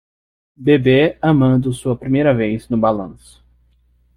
Uitgespreek as (IPA)
/baˈlɐ̃.su/